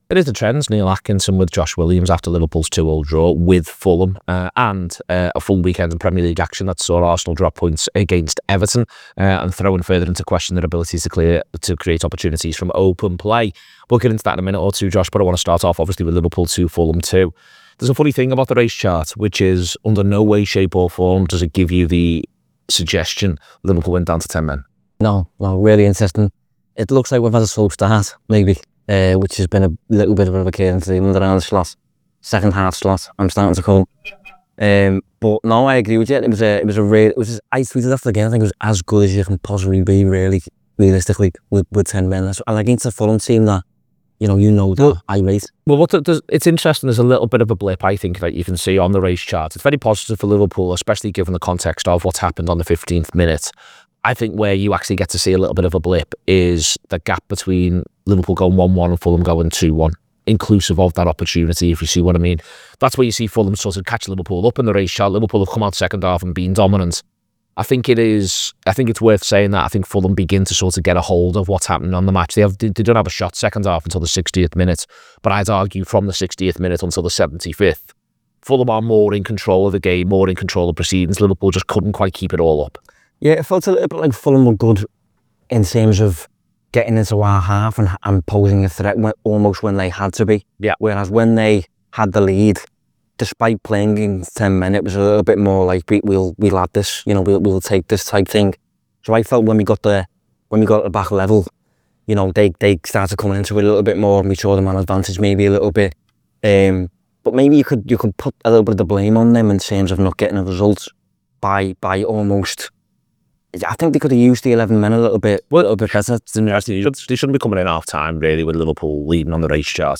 Podcast explaining the theory behind the statistics used in football, looking at the stats behind Liverpool 2 Fulham 2.